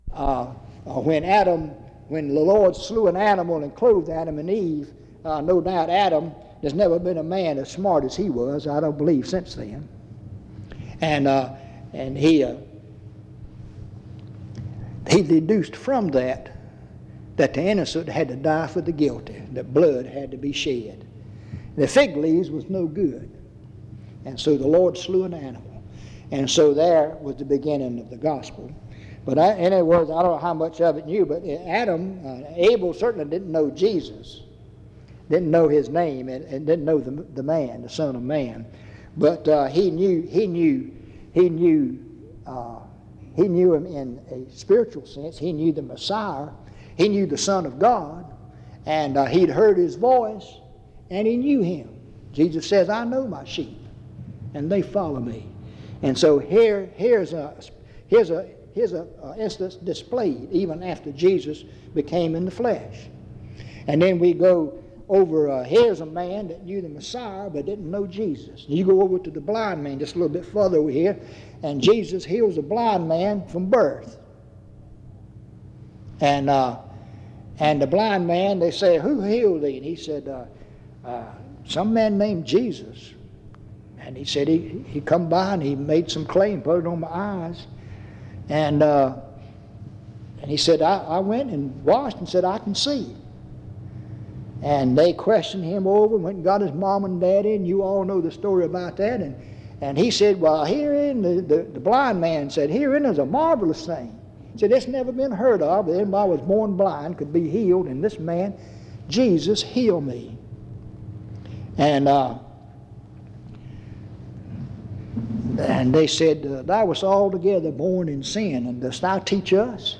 En Collection: Reidsville/Lindsey Street Primitive Baptist Church audio recordings Miniatura Título Fecha de subida Visibilidad Acciones PBHLA-ACC.001_033-A-01.wav 2026-02-12 Descargar PBHLA-ACC.001_033-B-01.wav 2026-02-12 Descargar